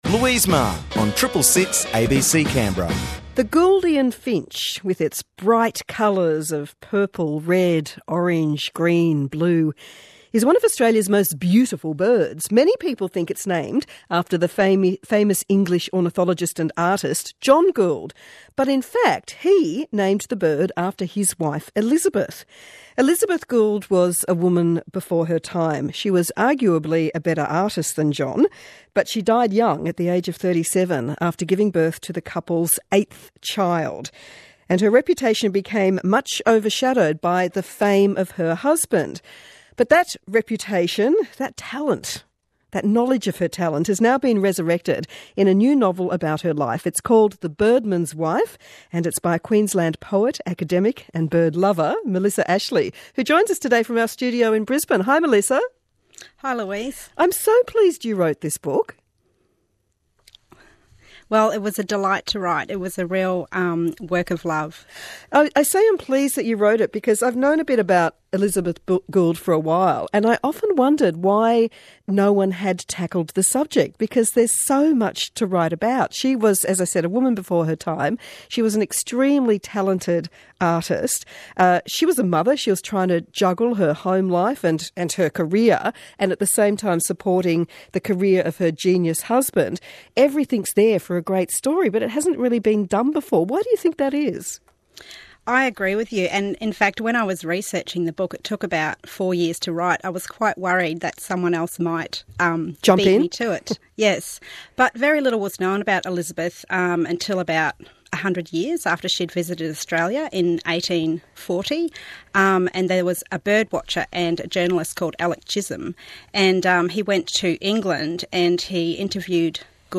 Posts about Interview